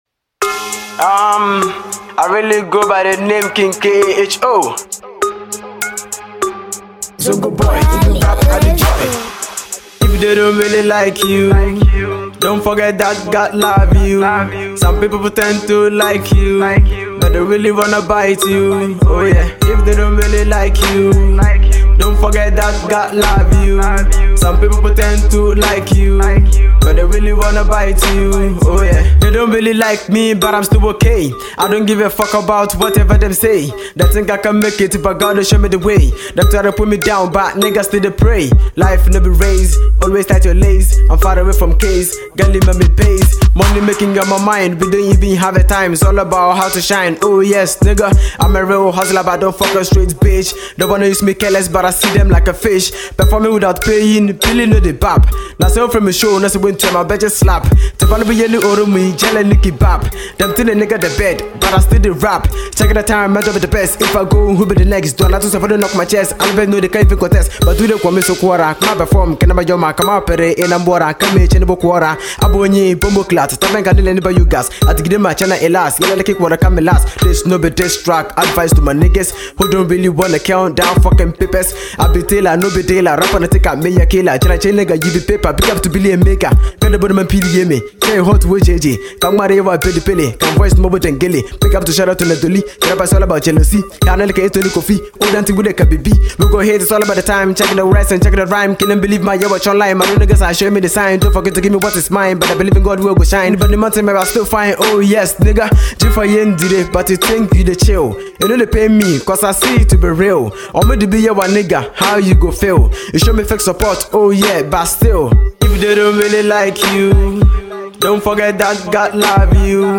very energetic and finest rapper